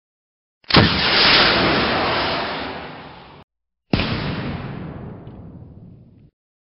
Звуки РПГ
На этой странице собрана коллекция мощных и реалистичных звуков выстрелов и взрывов из РПГ (ручного противотанкового гранатомета).
Звук выстрела ручного противотанкового гранатомета